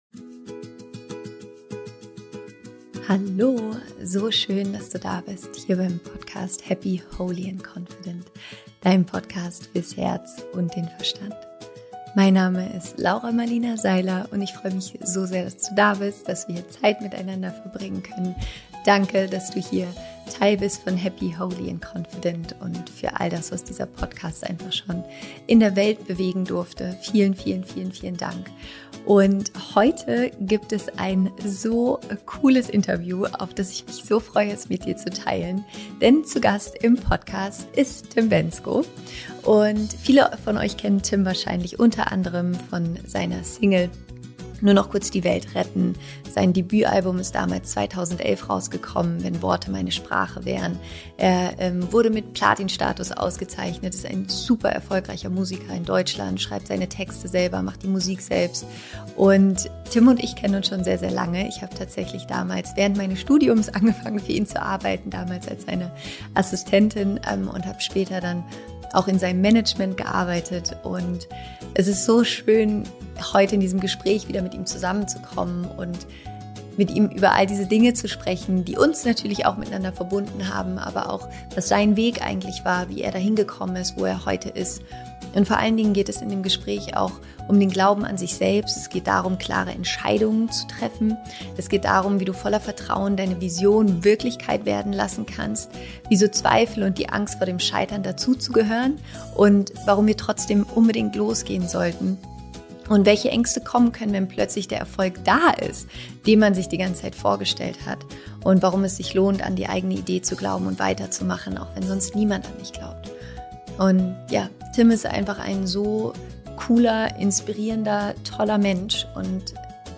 Denn ich spreche mit Tim Bendzko über seinen Weg zum erfolgreichen Musiker.